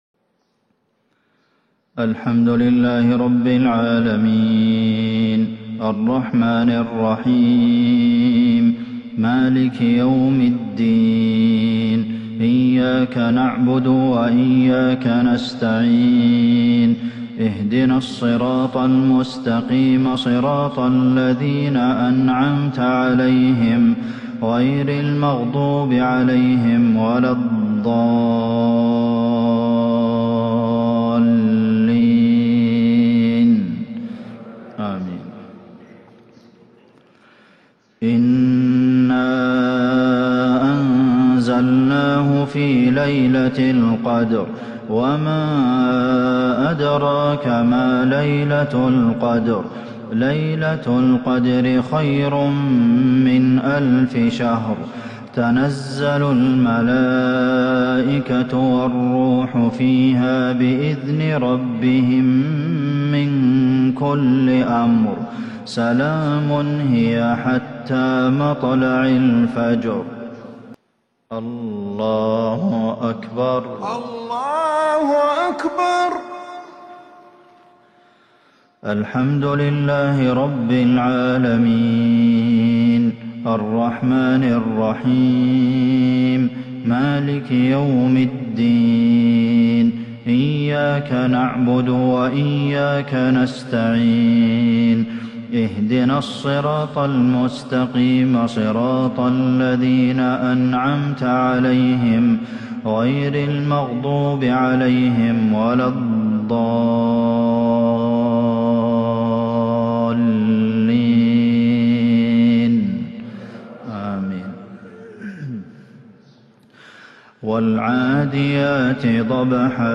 مغرب الخميس 2-5-1442هـ سورتي القدر والعاديات | Maghrib prayer Surah Al-Qadr and Al-'Adiyat 17/12/2020 > 1442 🕌 > الفروض - تلاوات الحرمين